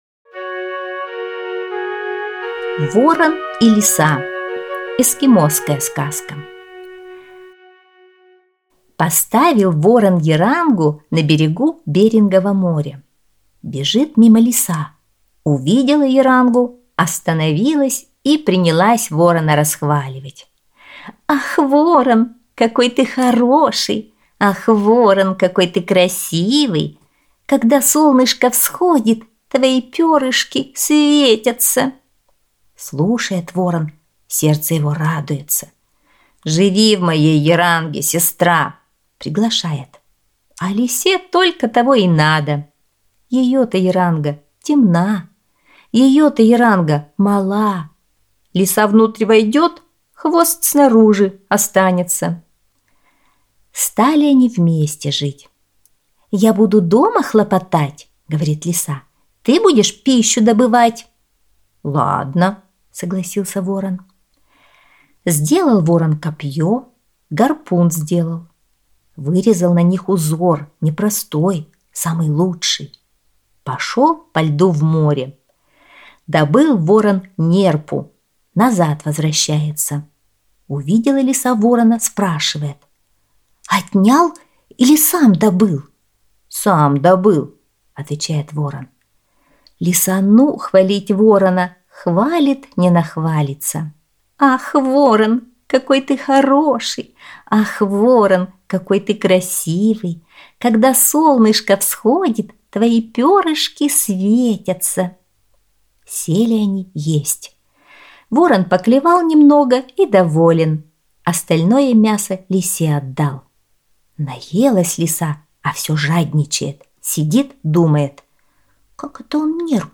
Эскимосская аудиосказка